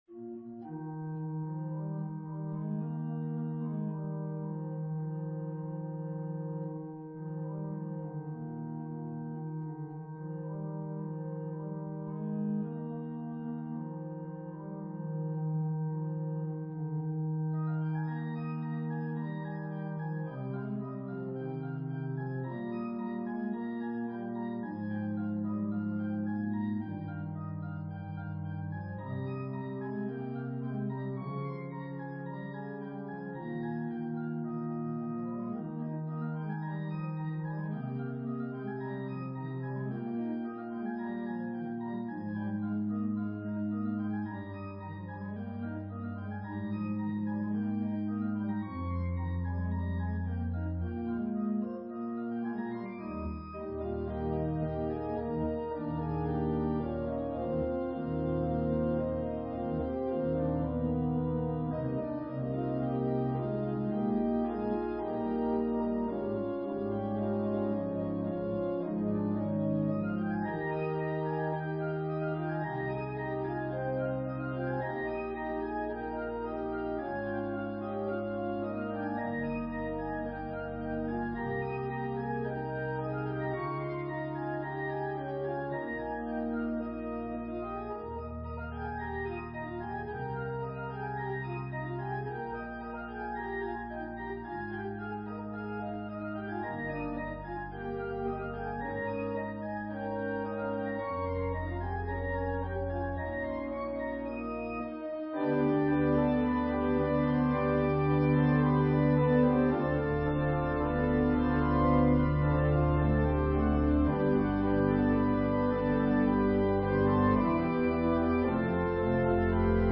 An organ solo version